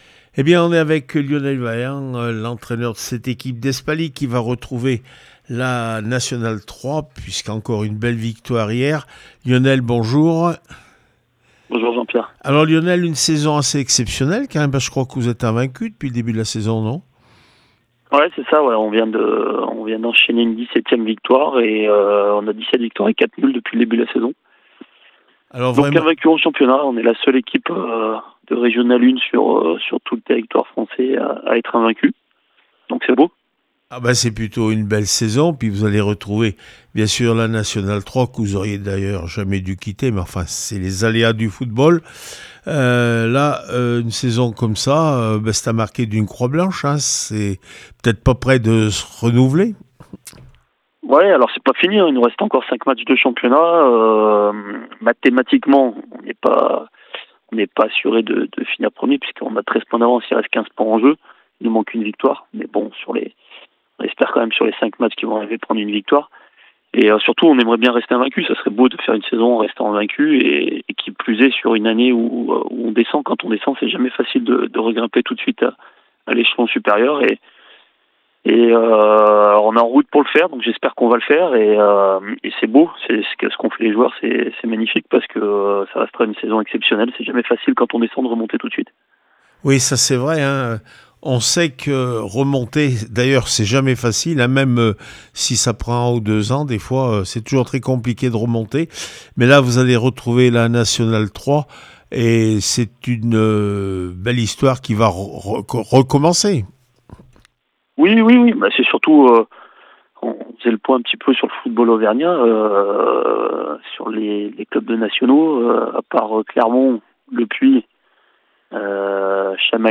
21 avril 2026   1 - Sport, 1 - Vos interviews
r1 foot fc Espaly 4-1 Thiers réaction après match